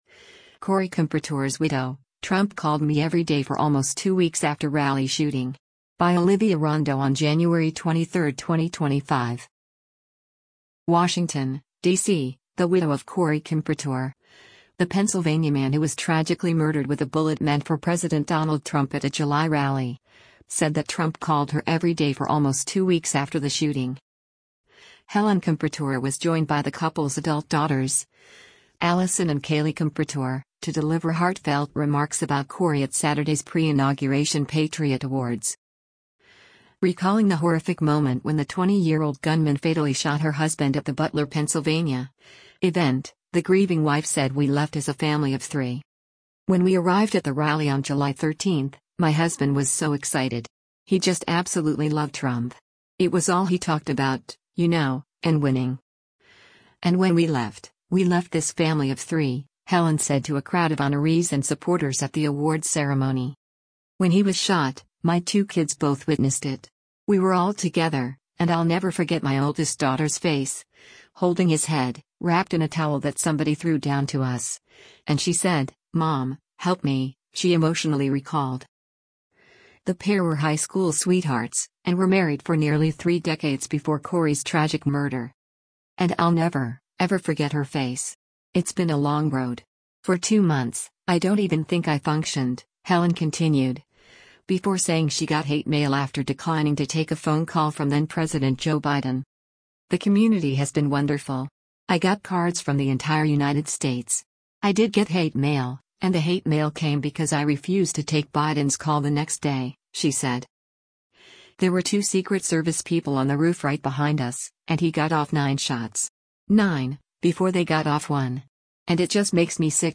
at Saturday’s pre-inauguration Patriot Awards